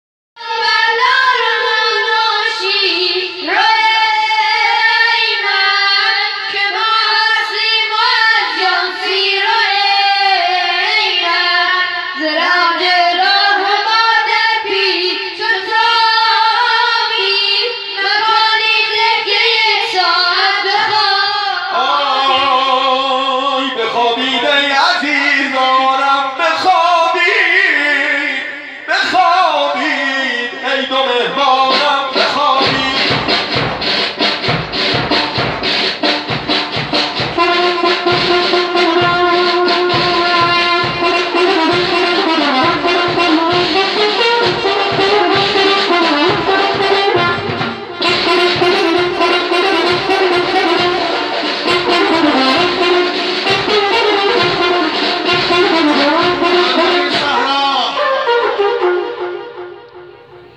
Attendance at a taʿziyeh performance is an intense sonic experience, and the taʿziyeh-khanan (performers) are trained in a special range of vocal techniques, some bordering on the operatic. Modern day performances use amplification, and the declamatory style of the antagonists is exaggerated with an echo effect.
The voices of the audience—calling in support of the protagonists, for blessings upon them, and audibly weeping at moments of sadness—are an integral part of the soundscape.
In this clip (audio 1), two boy performers playing Moslem’s sons Mohammad and Ebrahim sing together in conversation with a shepherd who has offered them shelter while they evade capture (Moslem b. ʿAqil, Hosayn’s cousin, was sent as a messenger to Kufa and killed there shortly before the battle of Karbala). The drums and trumpet that follow the exchange accompany the arrival of another visitor to the shepherd’s house.
Performance of Shahadat-e do teflan-e Moslem (The Martyrdoms of the Two Children of Moslem), temporary hosayniyeh (repurposed mechanic’s workshop), Khiyaban-e Kargar, Gomrok, Tehran (map). 5 Moharram 1439/26 September 2017.